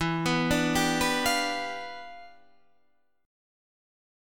Listen to Em7b9 strummed